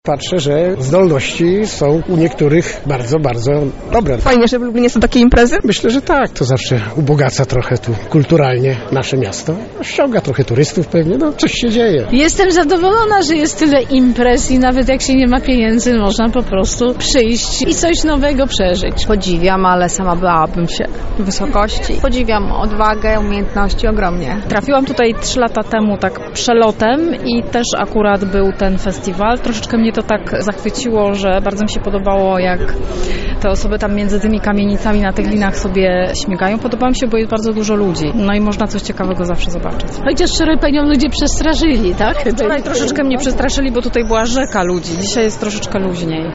Za nami kolejna edycja Carnavalu Sztukmistrzów.